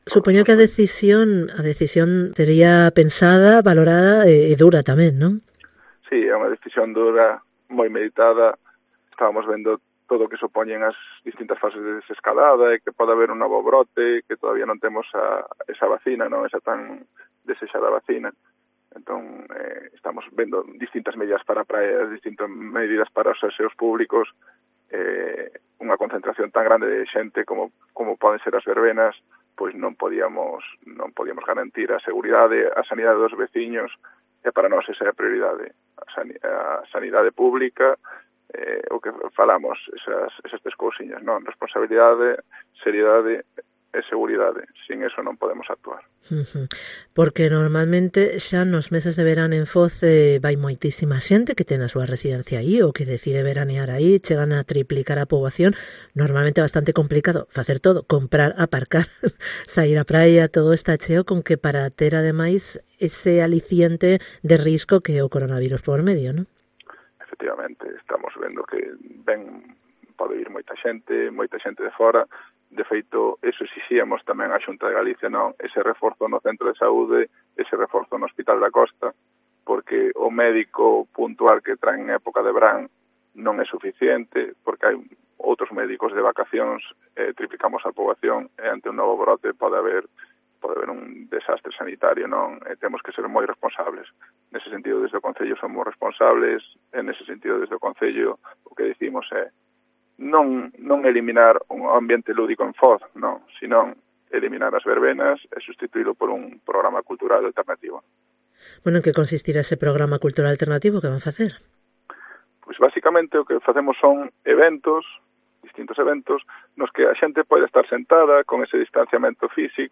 Entrevista con FRAN CAJOTO, alcalde de Foz